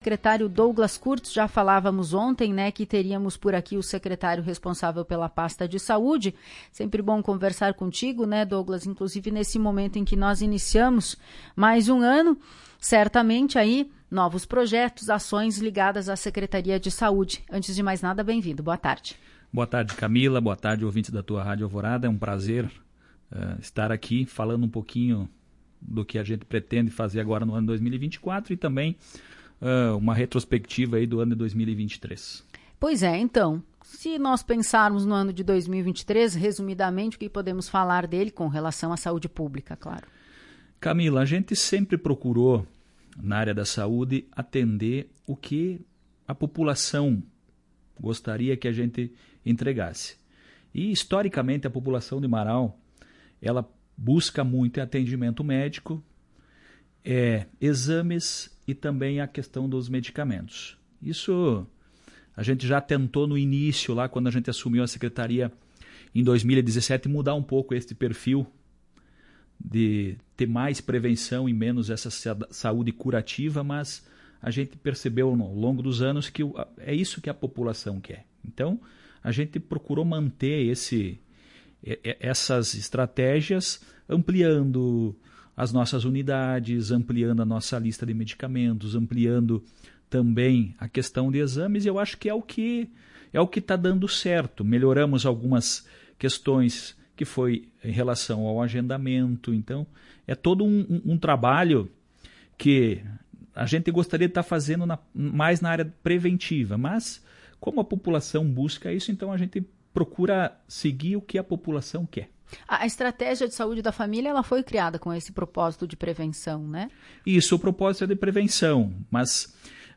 As informações foram confirmadas pelo secretário da pasta, Douglas Kurtz, em entrevista à Tua Rádio Alvorada.
No player de áudio acompanhe a íntegra da entrevista